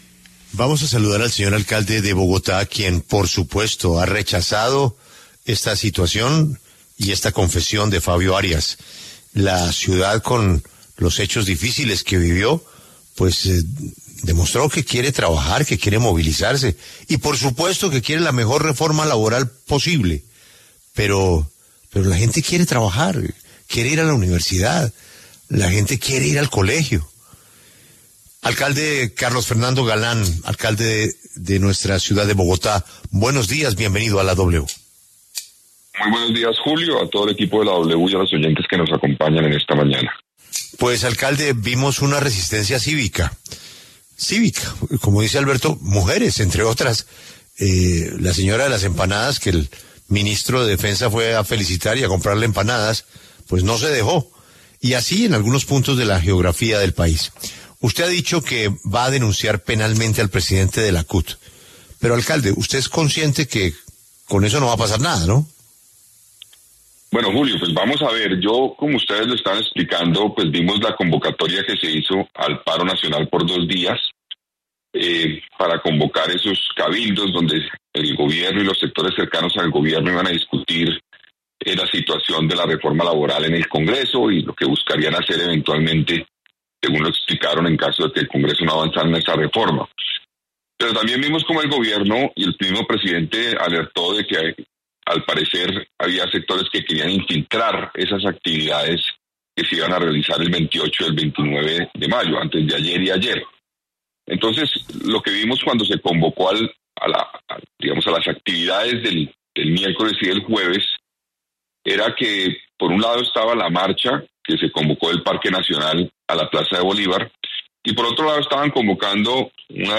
Alcalde Galán habla en La W y advierte que impondrá acciones legales contra el presidente de la CUT